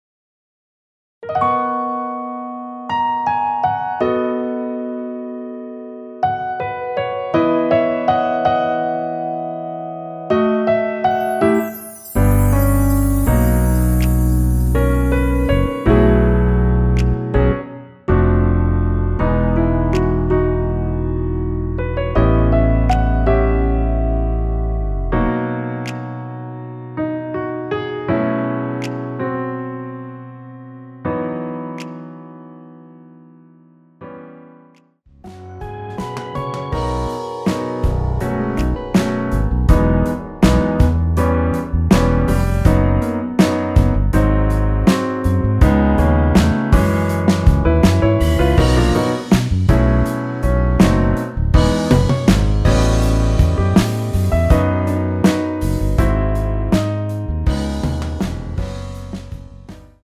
원곡(4분 56초)이 엔딩이 길고 페이드 아웃으로 끝나서
라이브 하시기 좋게 노래 끝나고 바로 끝나게 4분 36초로 편곡 하였습니다.(코러스 MR 미리듣기 참조)
원키에서(-1)내린 MR입니다.
앞부분30초, 뒷부분30초씩 편집해서 올려 드리고 있습니다.
중간에 음이 끈어지고 다시 나오는 이유는